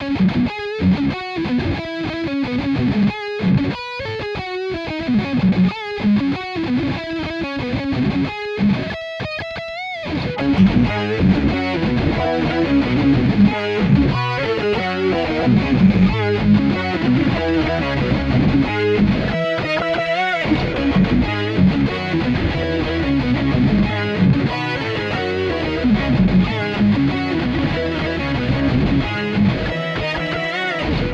man kann den vocalsynth2 auch ganz einfach als gitarreneffekt benutzen...
vocalsynth4git.mp3